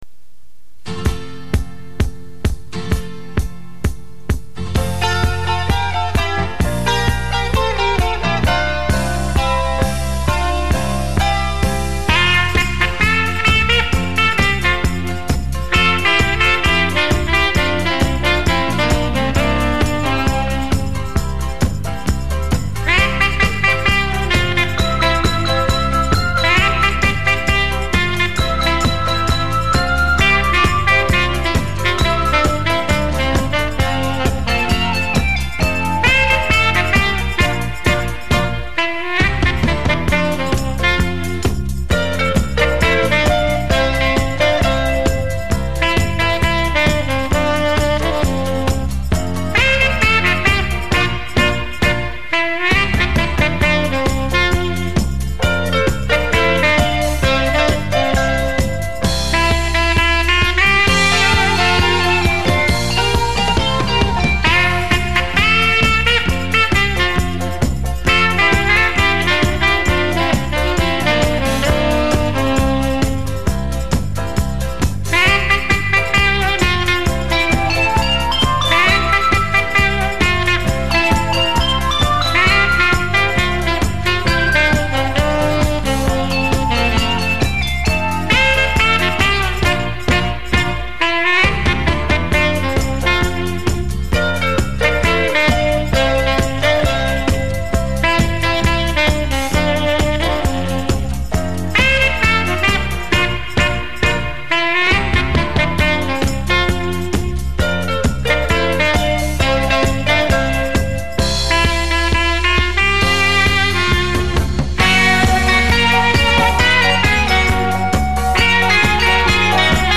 音乐介质:原版磁带转wav